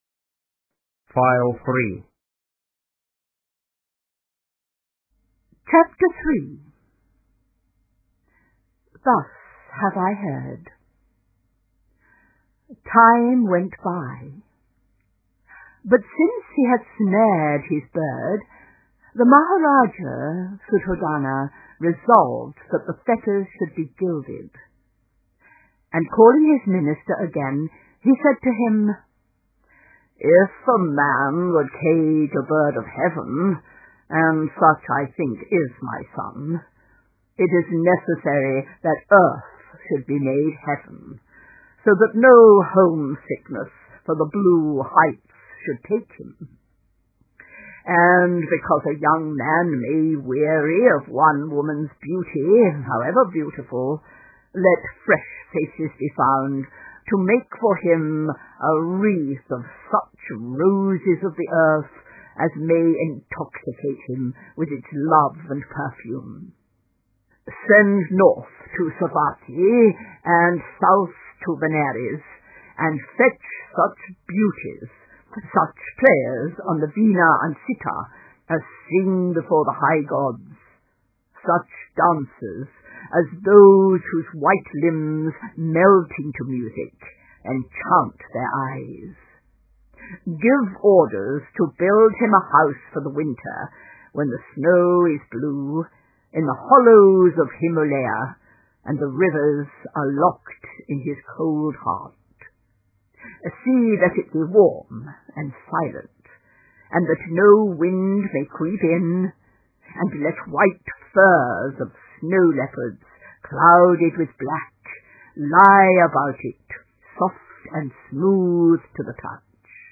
Life of Buddha (Audio Book)